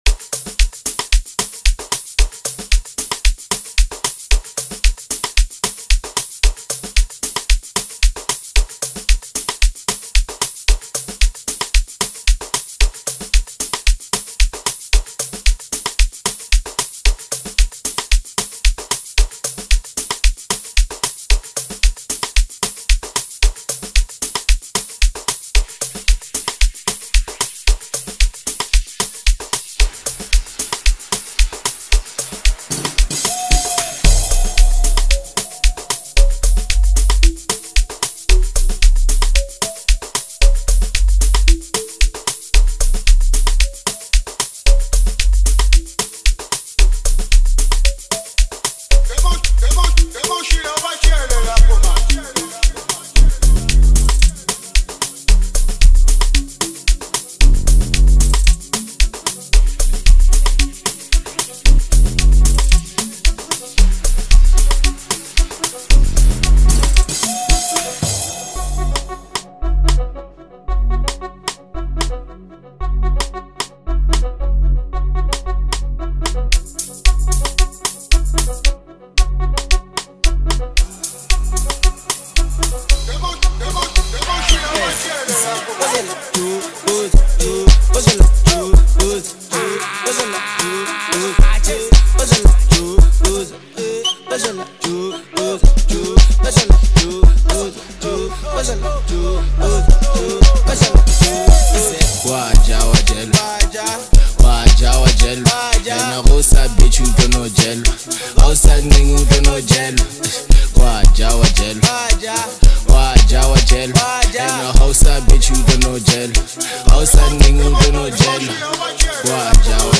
dynamic and polished track